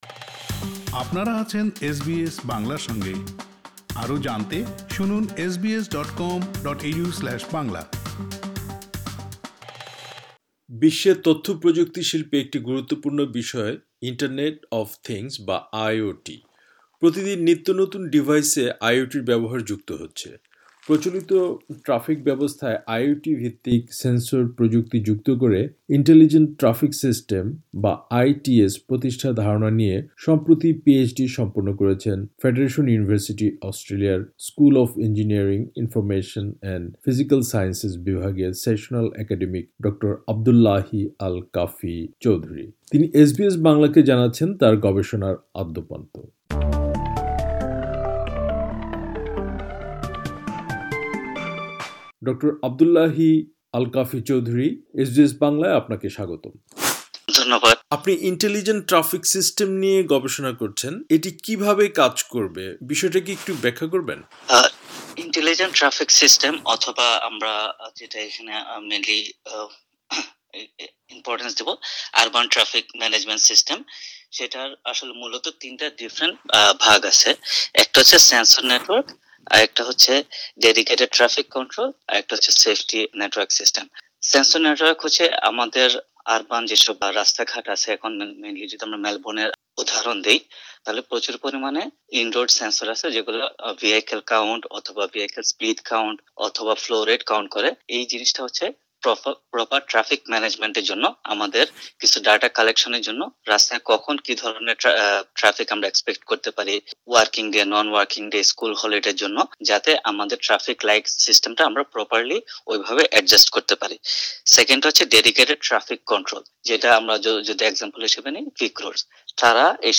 পুরো সাক্ষাৎকারটি